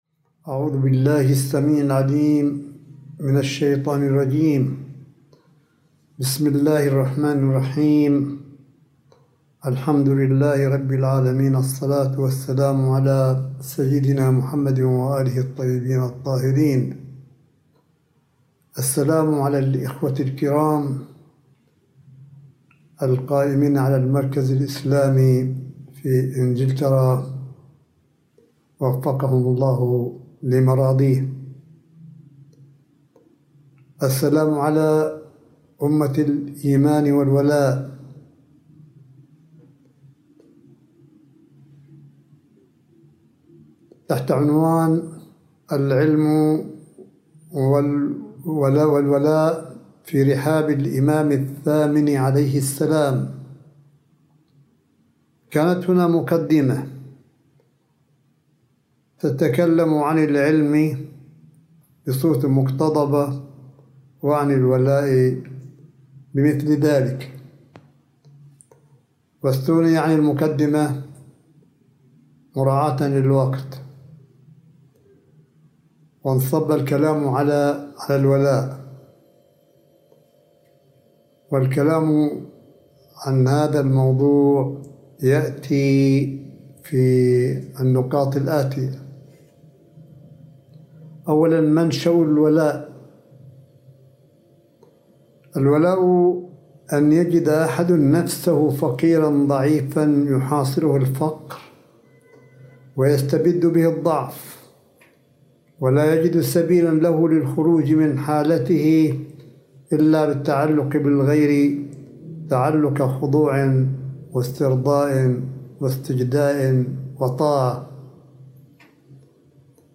ملف صوتي لكلمة سماحة آية الله الشيخ عيسى أحمد قاسم حفظه الله بمناسبة مولد الامام الرضا (ع) – 02 يوليو 2020م